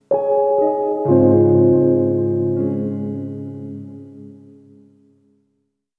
Treated Piano 08.wav